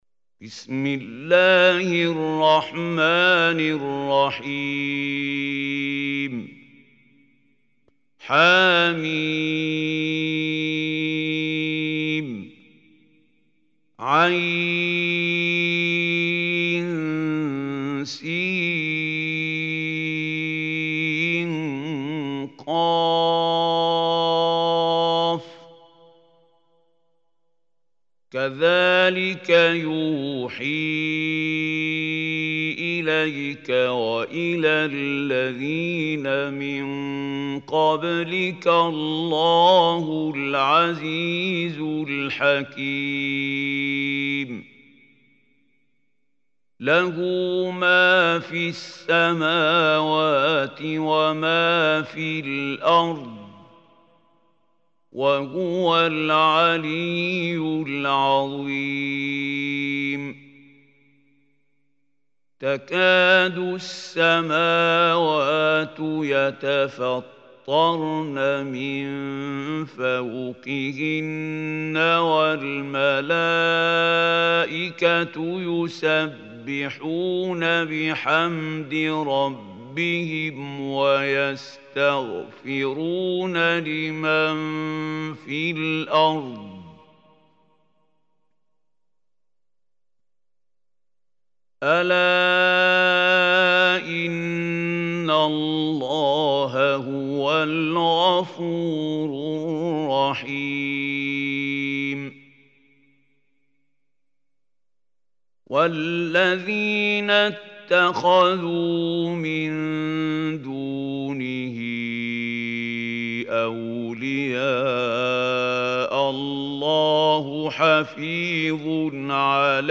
Surah Ash Shuraa Recitation by Mahmoud Hussary